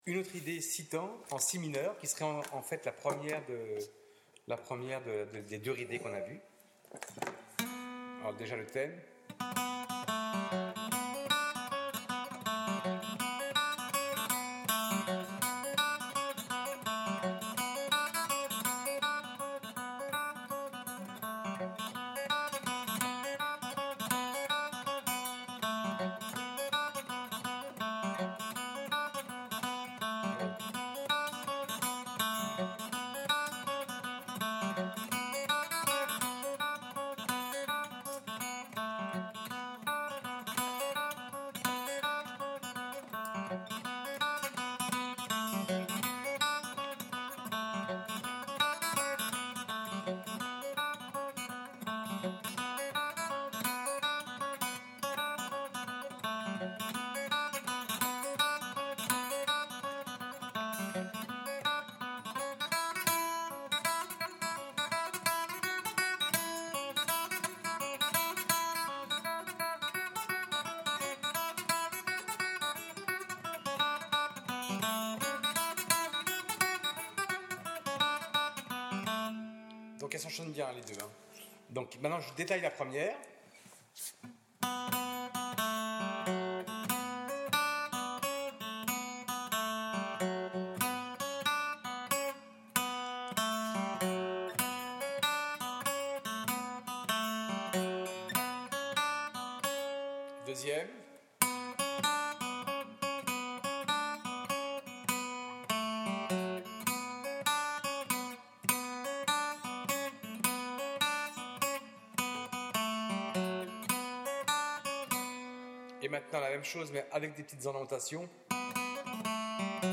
:mp3:2013:stages:guitare